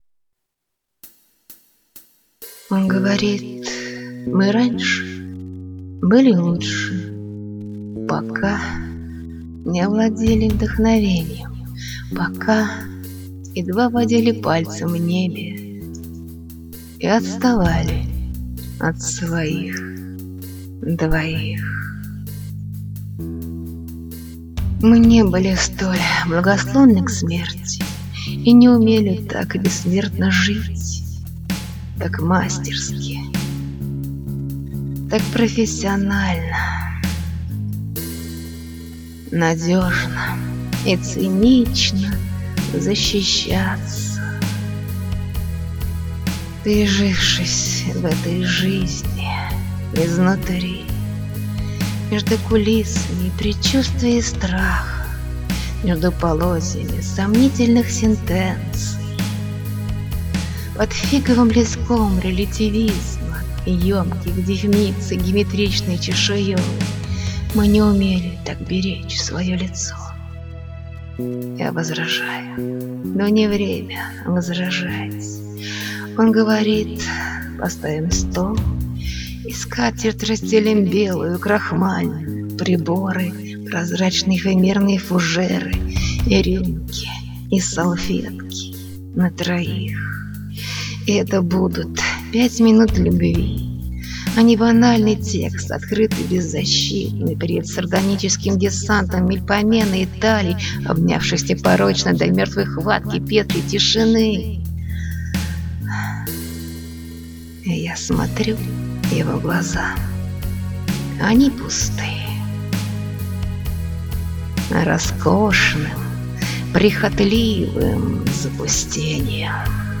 «Декламация»
хороший речитатив (интеллектуально-привлекательный тембрально)
прекрасный рэп, замечательно подобрана музыка